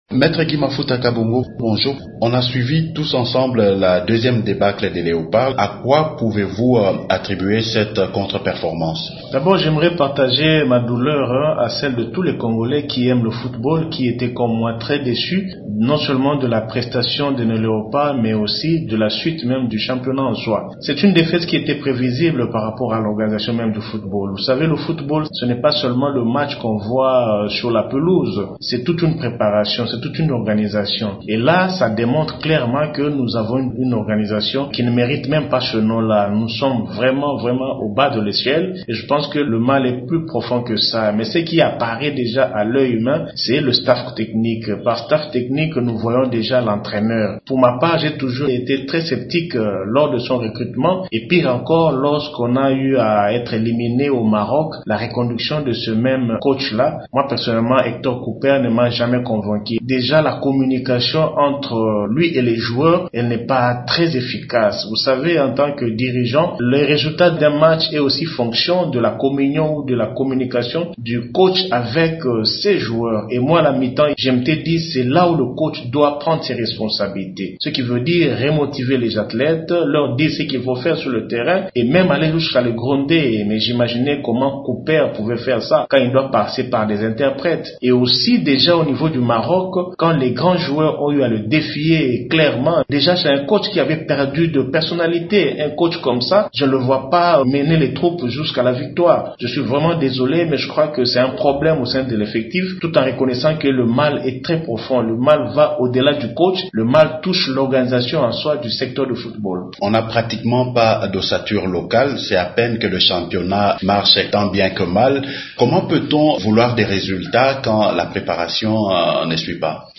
Invité de Radio Okapi, il a déploré notamment le manque d’organisation au sein du staff technique de l’équipe nationale de football de la RDC.